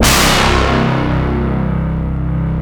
ABYSS C2.wav